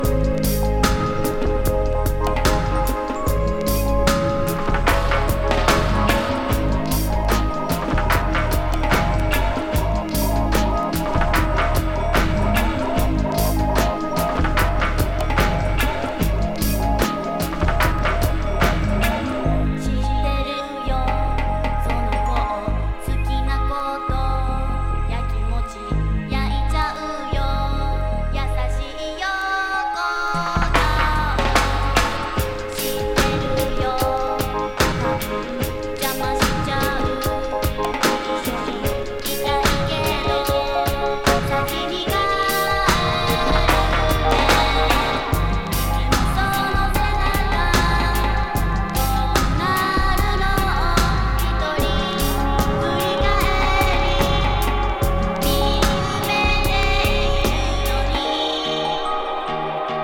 極上のLovers Mix！